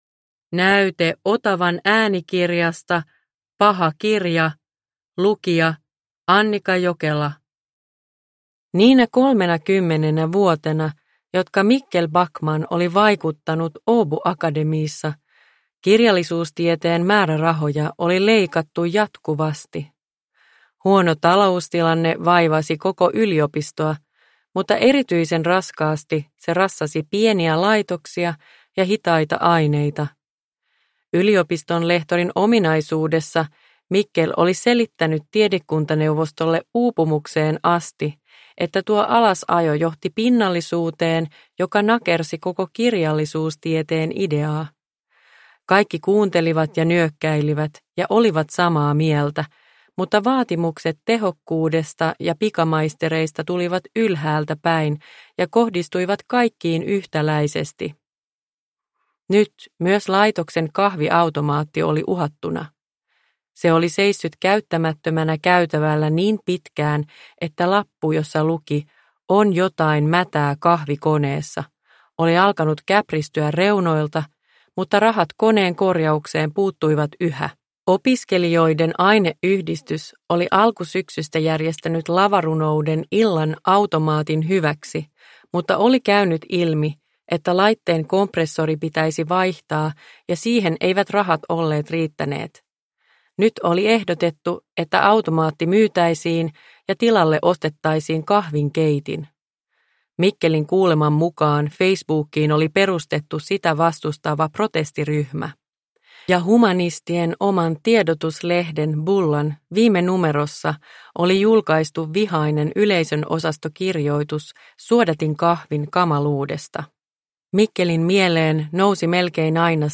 Paha kirja – Ljudbok – Laddas ner